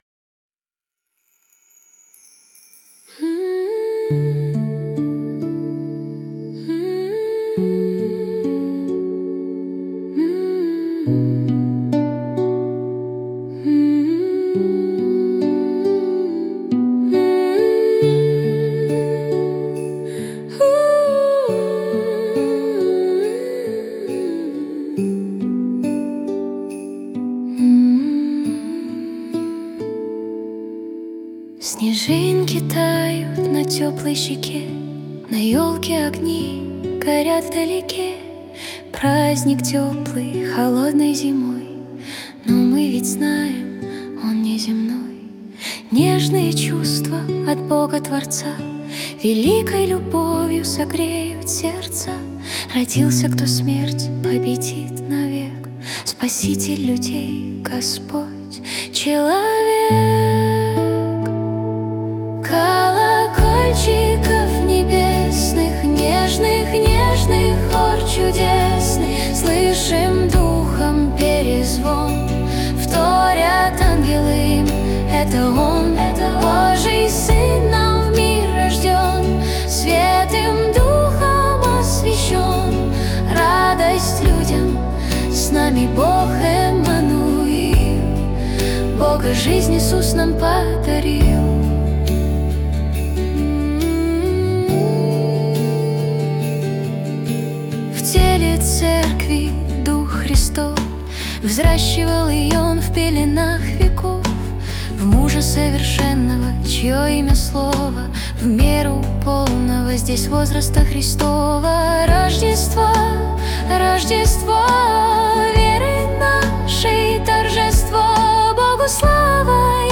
песня ai
461 просмотр 1172 прослушивания 94 скачивания BPM: 70